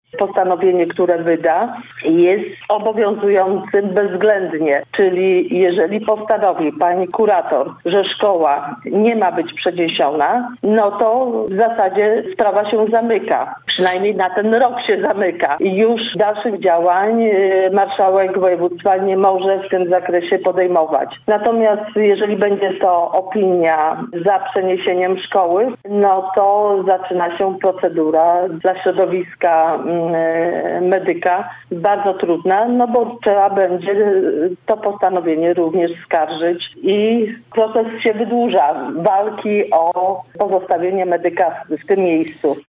'- Oczekujemy na opinię Lubuskiego Kuratora Oświaty w sprawie przyszłości „Medyka” - mówiła dziś w „Rozmowie po 9” Bożena Ronowicz.